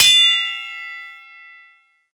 🌲 / mods PD2-AdvancedCrosshairs-r39 assets snd hitsounds
tf2_hit.ogg